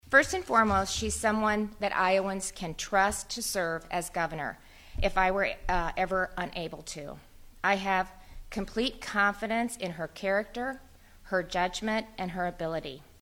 REYNOLDS SAYS SHE HAS COMPLETE CONFIDENCE IN COURNOYER’S ABILITY TO SERVE IOWA: